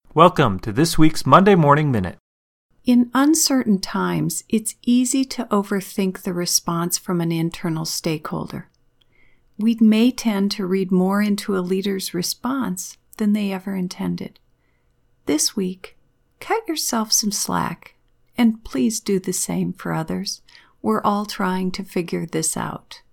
Your message is food for thought – as always – and your voice itself is so very soothing.